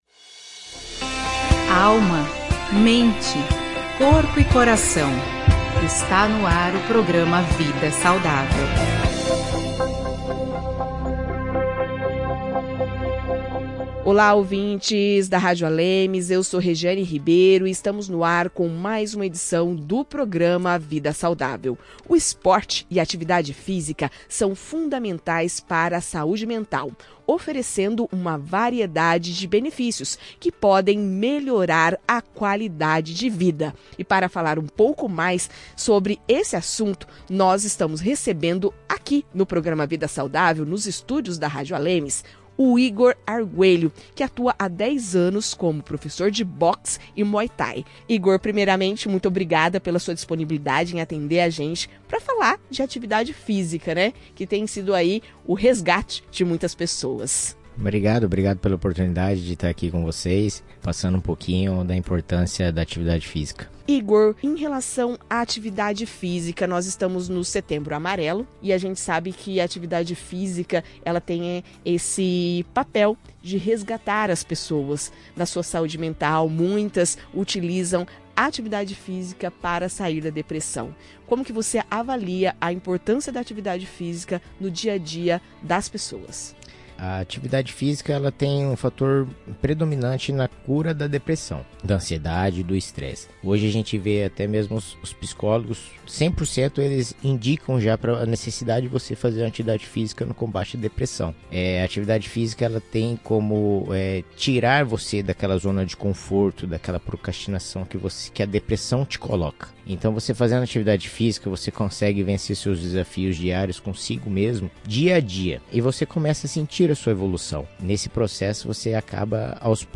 traz uma entrevista com o professor de boxe e muay thai